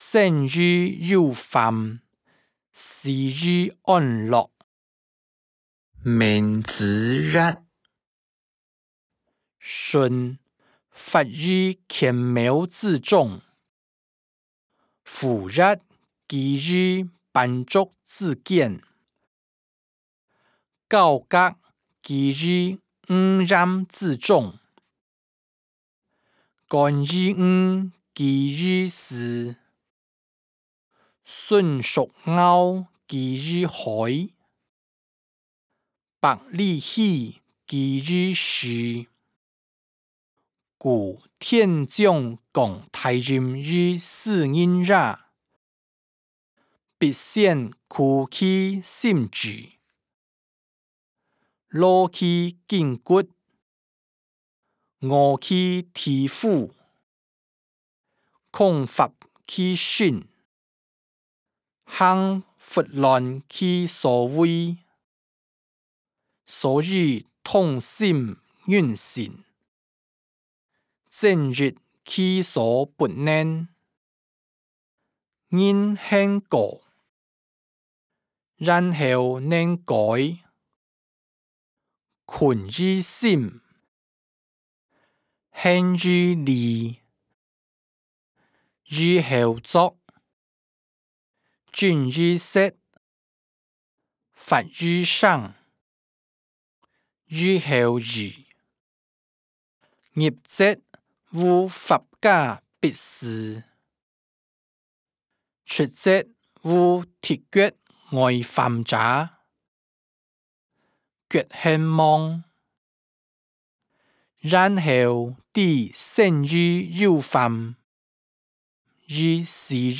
經學、論孟-生於憂患，死於安樂音檔(海陸腔)